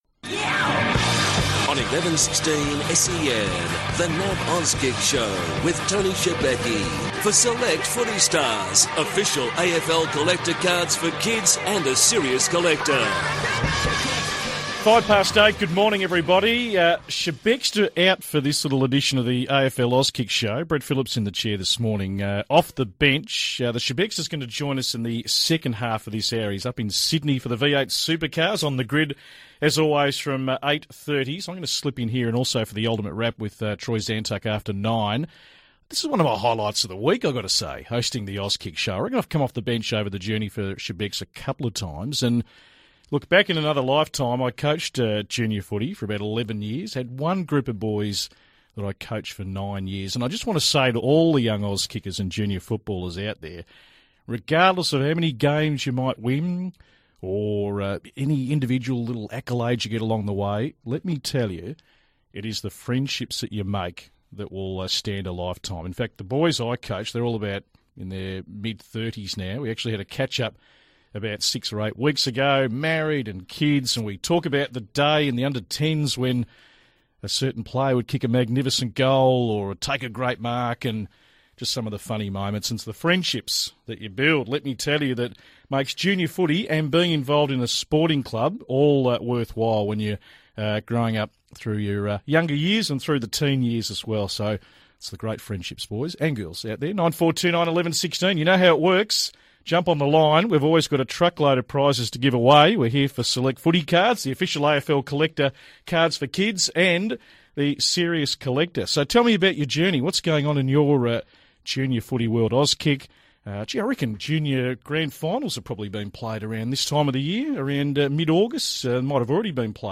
takes all your calls on The Auskick Show.